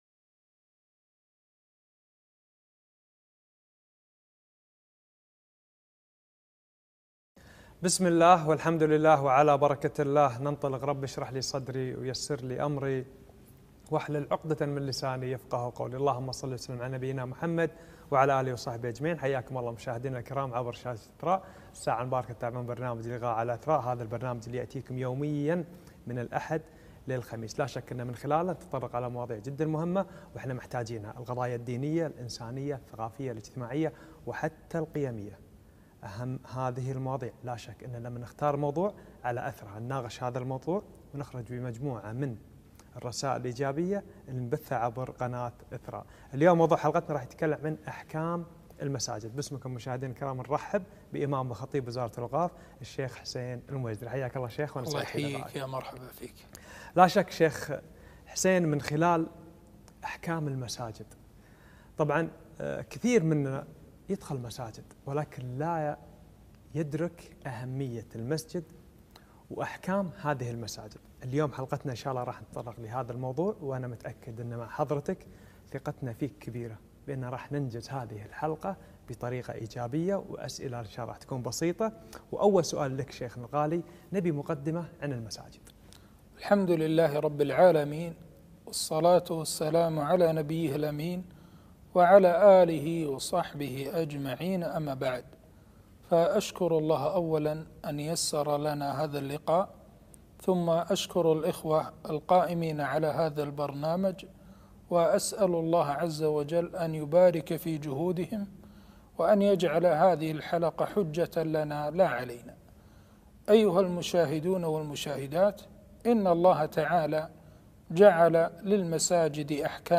أحكام المساجد - لقاء تلفزيوني